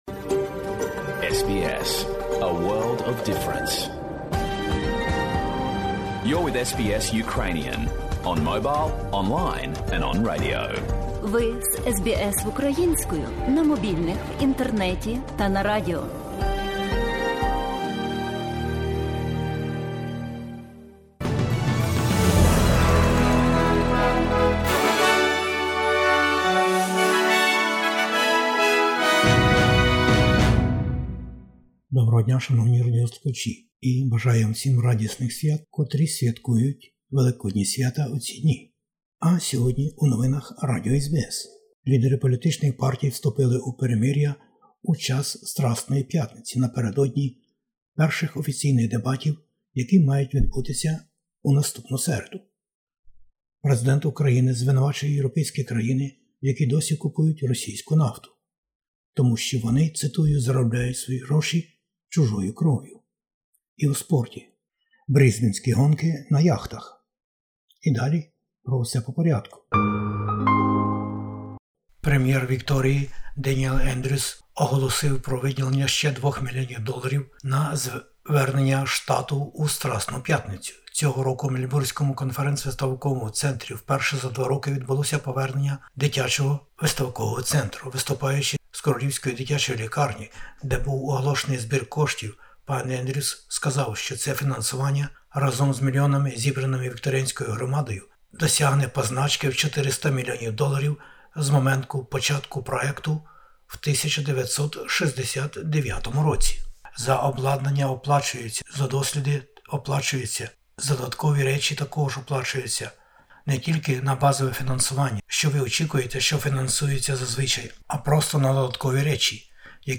Бюлетень новин SBS українською мовою. Великодній час у період труднощів на планеті.